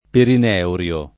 perinervio [perin$rvLo] s. m. (med.); pl. ‑vi (raro, alla lat., -vii) — anche perineurio [